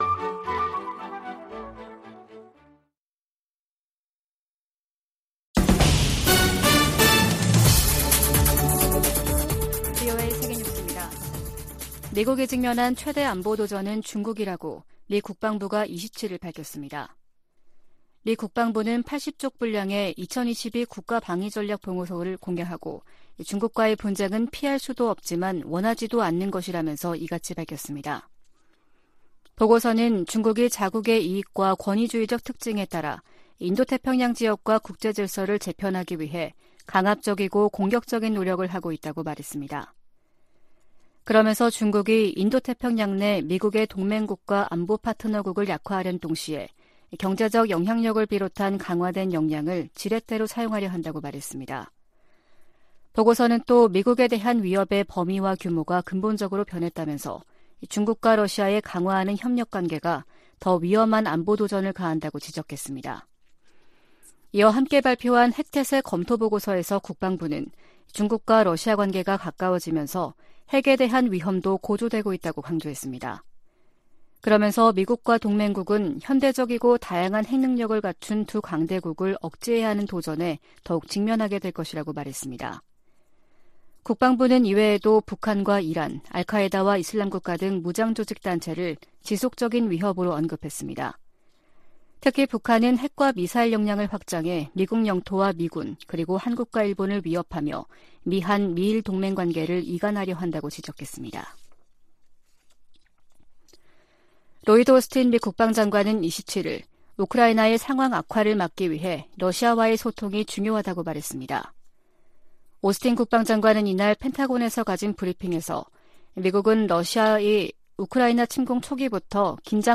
VOA 한국어 아침 뉴스 프로그램 '워싱턴 뉴스 광장' 2022년 10월 29일 방송입니다. 북한이 28일 동해상으로 단거리 탄도미사일(SRBM) 두 발을 발사했습니다. 북한이 7차 핵실험에 나선다면 국제사회가 엄중한 대응을 할 것이라고 백악관 고위관리가 밝혔습니다. 미 국방부는 북한 정권이 핵무기를 사용하고 살아남을 수 있는 시나리오는 없다고 경고했습니다.